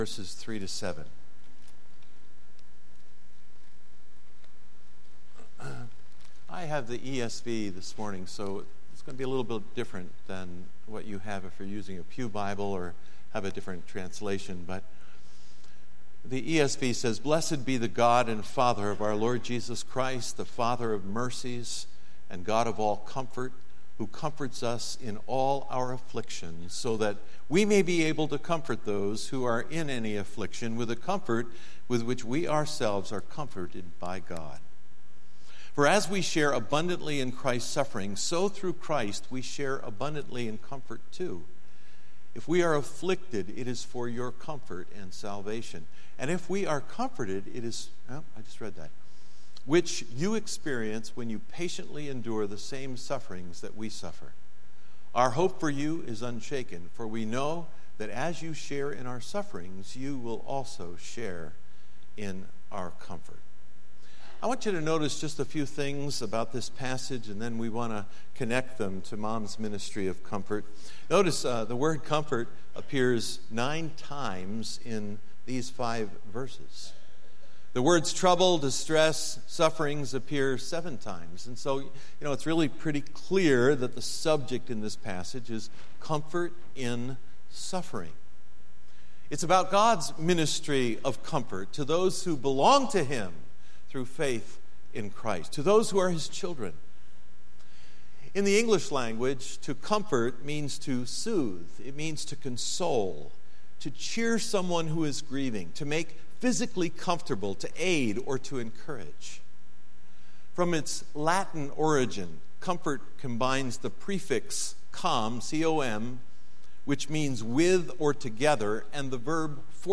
Sermons Archive - Mayfair Bible Church